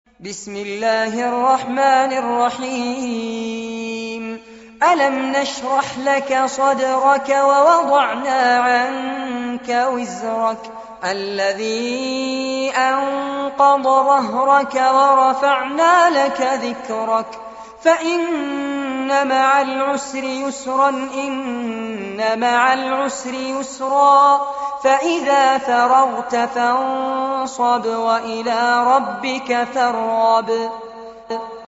عنوان المادة سورة الشرح- المصحف المرتل كاملاً لفضيلة الشيخ فارس عباد جودة عالية